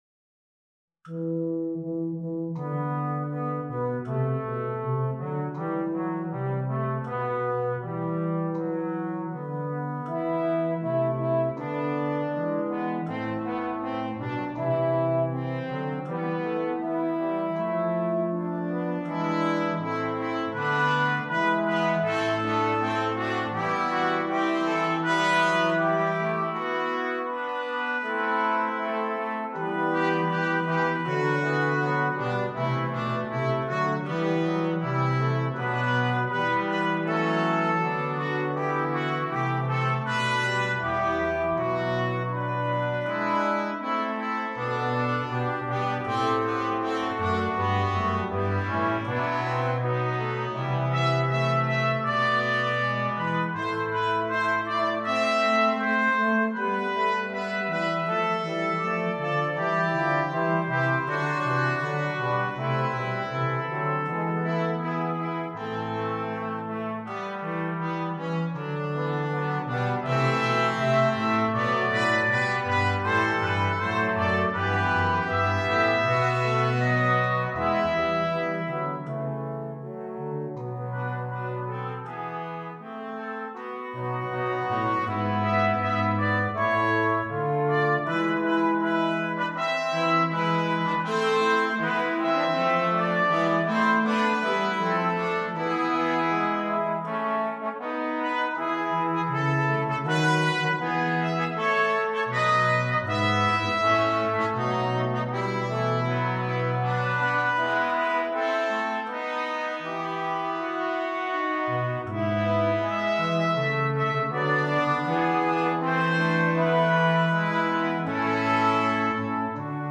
Quintet
sans instrument solo
Classique
Partie 1: Trompette sib, Cornet à Pistons sib
Partie 3: Cor d’harmonie
Partie 4: Trombone – Clé de fa
Partie 5: Tuba